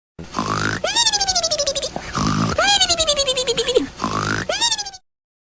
Cartoon Snore Mimimimimi